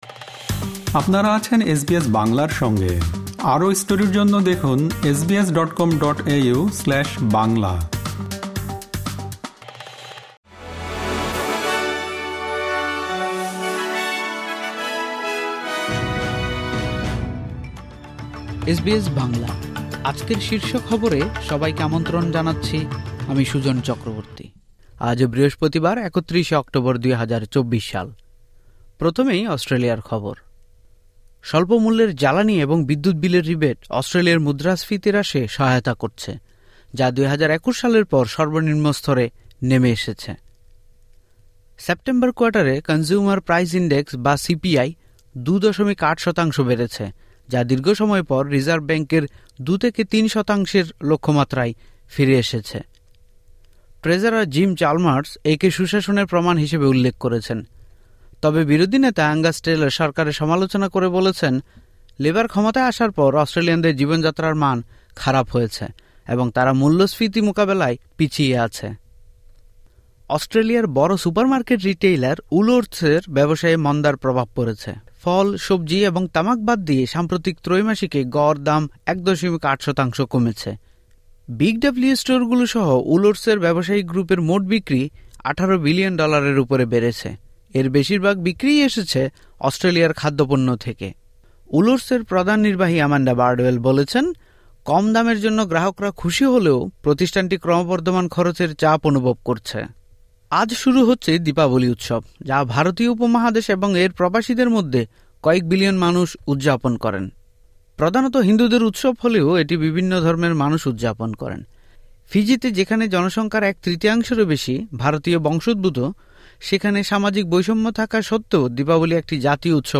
এসবিএস বাংলা শীর্ষ খবর: ৩১ অক্টোবর, ২০২৪